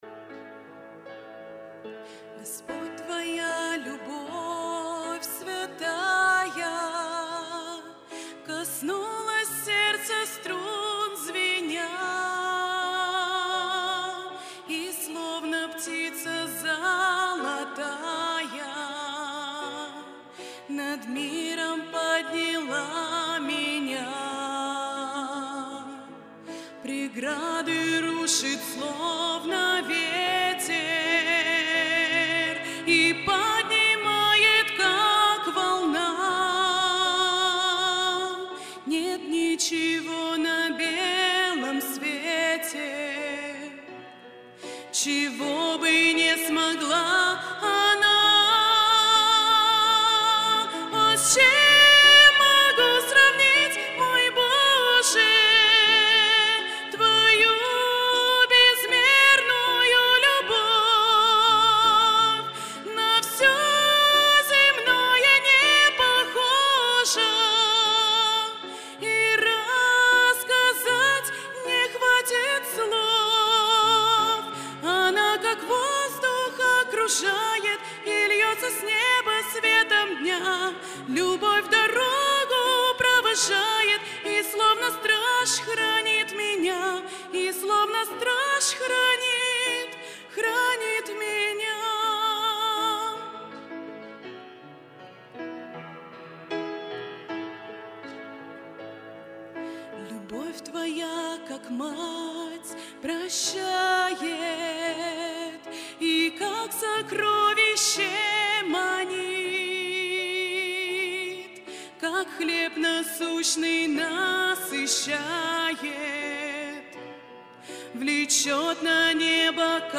Богослужение 29.01.2023
(Пение)